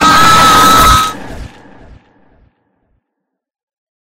jumpscare_huggy.mp3